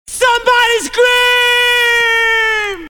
S-SOMEBODY-SCREAM-A.mp3